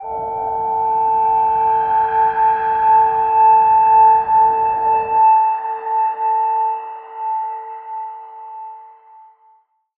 G_Crystal-A6-mf.wav